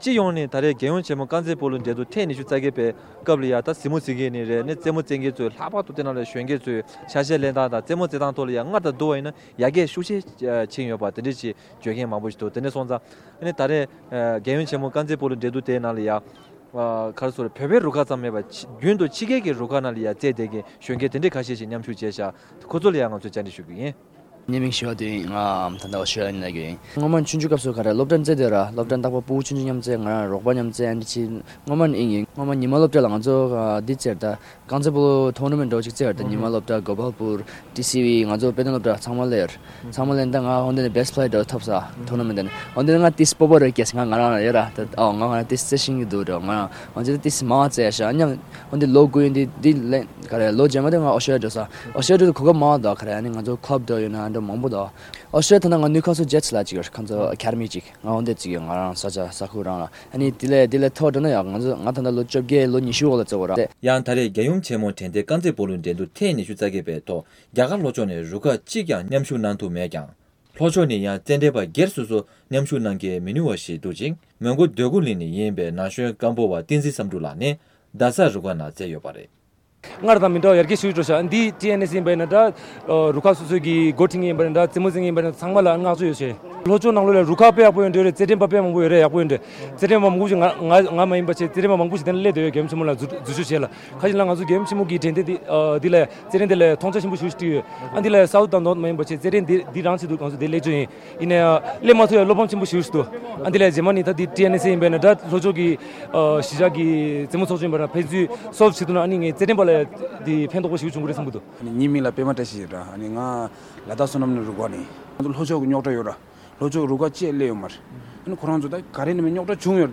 སྒྲ་ལྡན་གསར་འགྱུར། སྒྲ་ཕབ་ལེན།
༧རྒྱལ་ཡུམ་ཆེན་མོའི་རྗེས་དྲན་གསེར་གྱི་གཟེངས་རྟགས་རྐང་རྩེད་སྤོ་ལོའི་རྩེད་འགྲན་ཐེངས་ ༢༨ དང་འབྲེལ་ནས་བཙན་བྱོལ་བོད་མིའི་རྐང་རྩེད་སྤོ་ལོའི་གནས་སྟངས་སྐོར་དང་རྐང་རྩེད་སྤོ་ལོ་རྩེད་མཁན་དང་གཟིགས་མོ་བ་འགའ་ཤིག་ལ་བཅར་འདྲི་གནང་བའི་སྐོར།